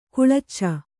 ♪ kuḷacca